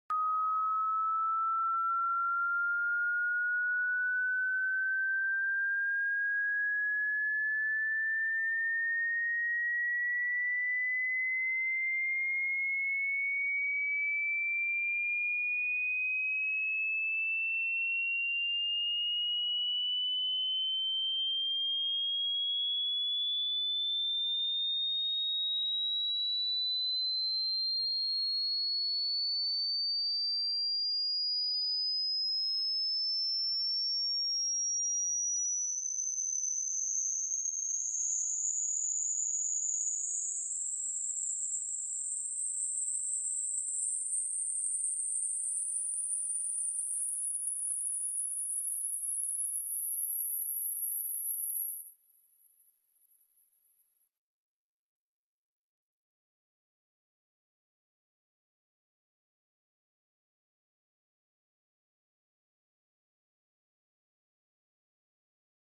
19kHz 🔊 Can You Still Hear It?